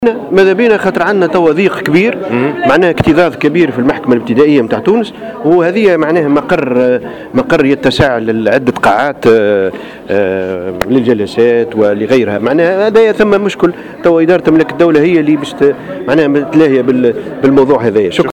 وأكد محمد صالح بن عيسى في تصريحات لـ"جوهرة أف أم" أنه أعلم وزير املاك الدولة والشؤون العقارية باهتمامه ببناية التجمع، مشيرا إلى أن وزارة العدل تعاني اكتظاظا بجميع مكاتبها وإداراتها وخاصة منها المحاكم.